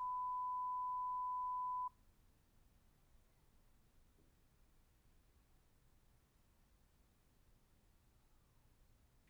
Now I’m attempting to do a bit more careful job of evaluating the H6 preamps by using a single mic source and a mono recording.
Then I turned down the tone by 40 dB and played the tone followed by silence and recorded that. I measured the tone and it showed a level of 55 dB, about the same sound level as a quiet office setting. I’m not reproducing the loud tone, but here is the quiet tone, followed by what passes for silence in my suburban bedroom studio.
Zoom H6 Low Volume Tone
Low-Tone-Zoom.wav